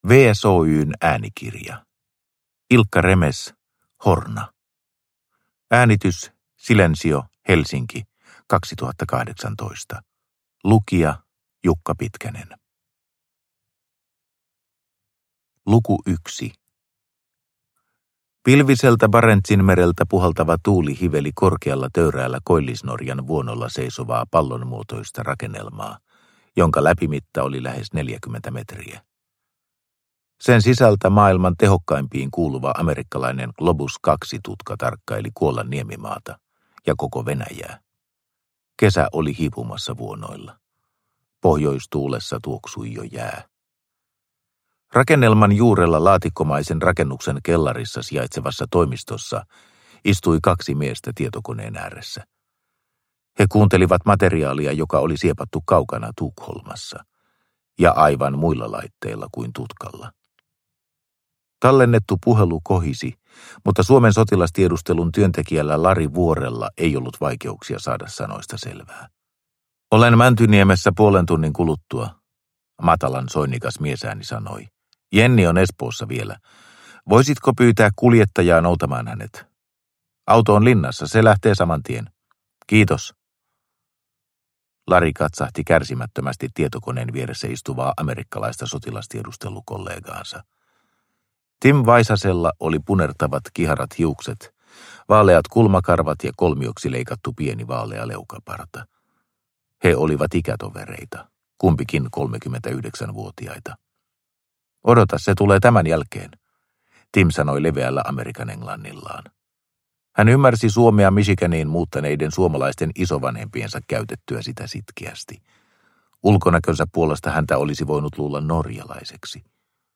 Horna – Ljudbok – Laddas ner